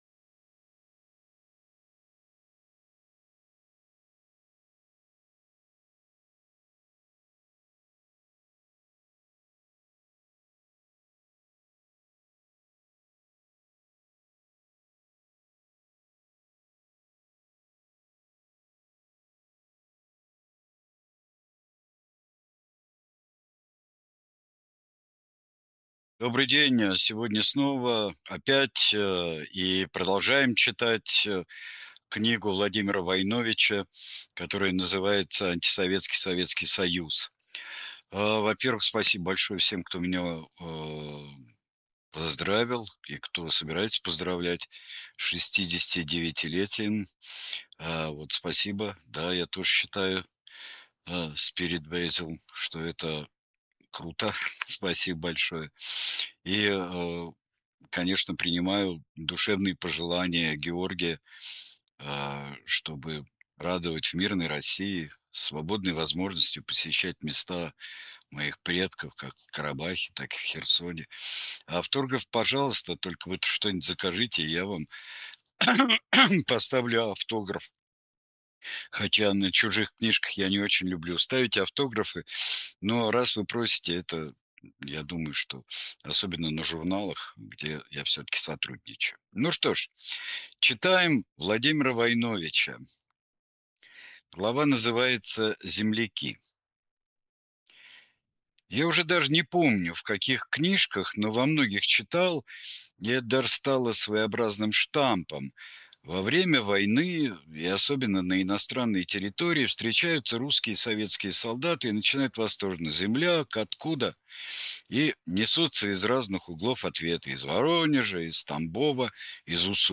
Произведение читает Сергей Бунтман